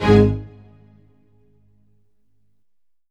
ORCHHIT G09L.wav